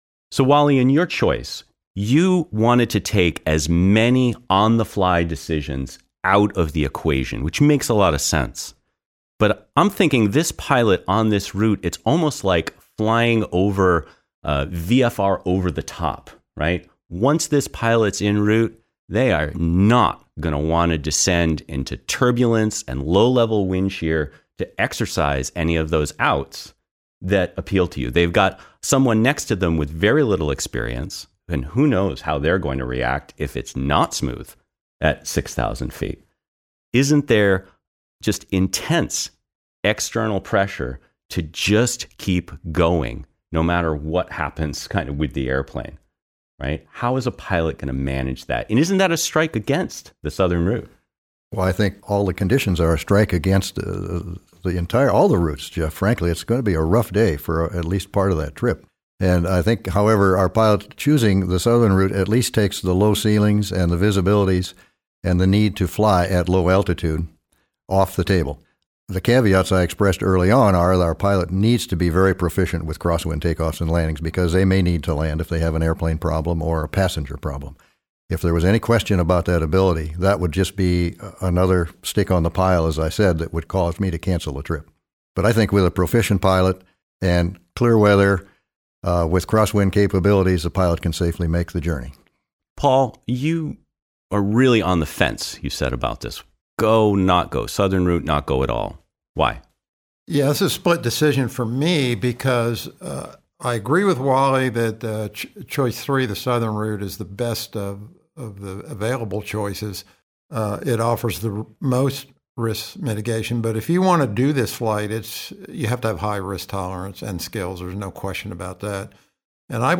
Listen in as our team of instructors discuss and debate the details of this scenario.